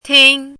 “汀”读音
tīng
汀字注音：ㄊㄧㄥ
国际音标：tʰiŋ˥
tīng.mp3